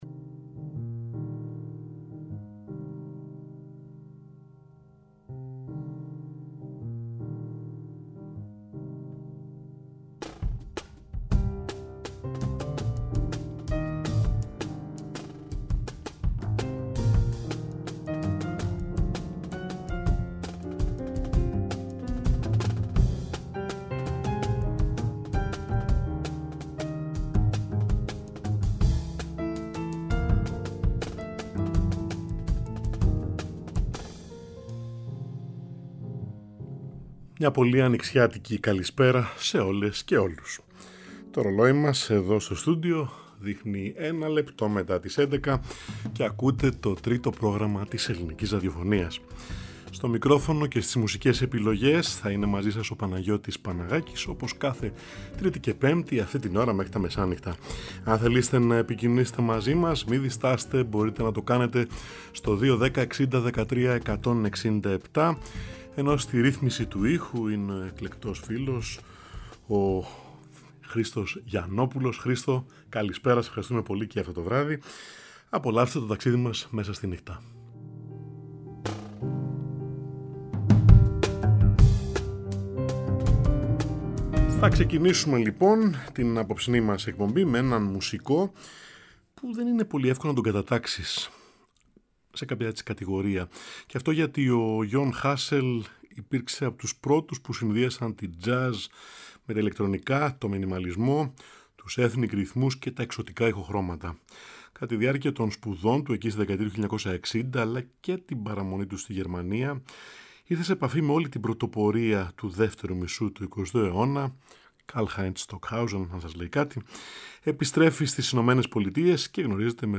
Κάθε Τρίτη και Πέμπτη στις έντεκα, ζωντανά στο Τρίτο Πρόγραμμα